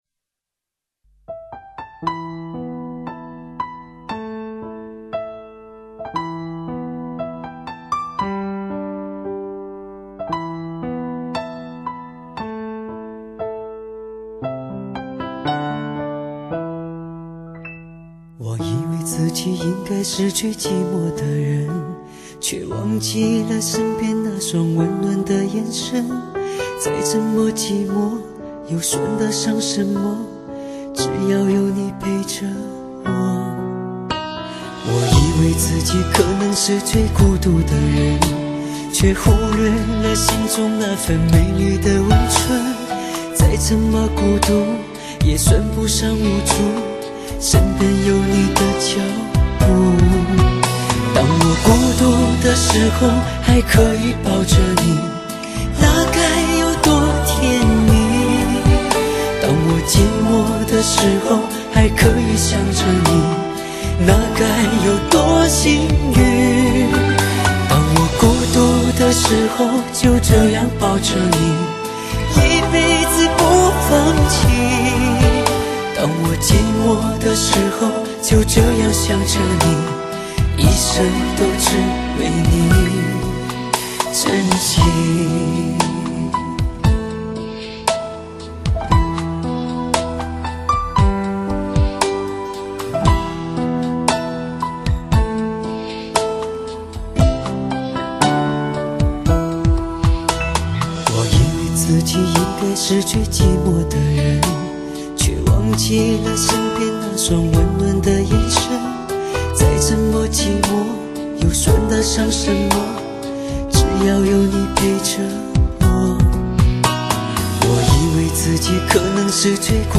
一辑超高品质的流行男声经典大碟，精选华语流行乐坛最发烧经典歌曲，曲目编选时尚完美，全程录制技艺精良！